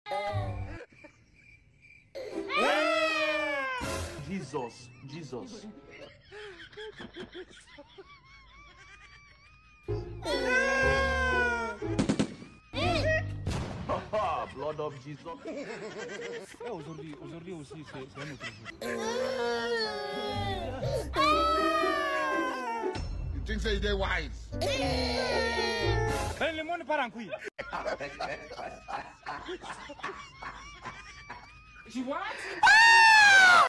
The Dramatic Cry 😂 The Sound Effects Free Download